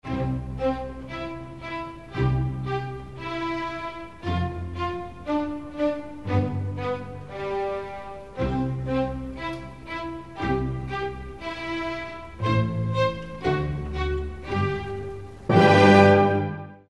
formal